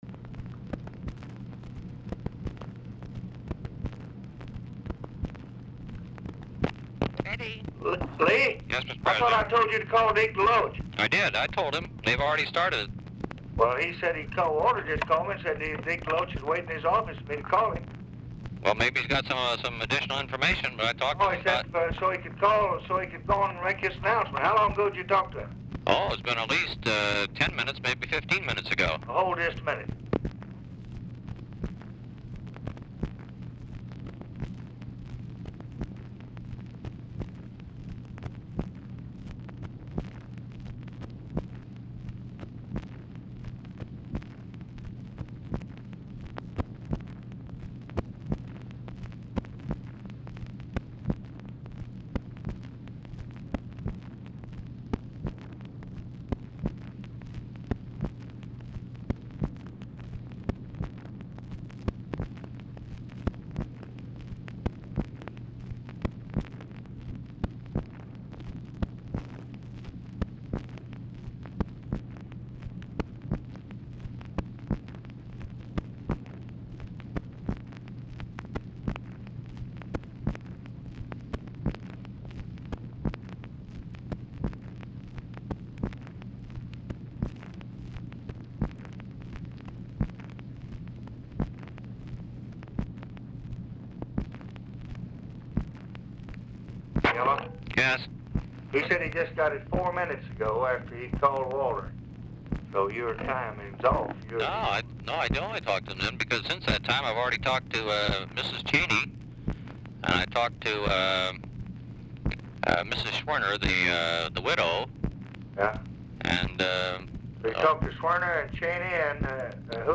Telephone conversation # 4701, sound recording, LBJ and LEE WHITE
WHITE ON HOLD FOR ABOUT 1:00 DURING CONVERSATION
Format Dictation belt
Location Of Speaker 1 Oval Office or unknown location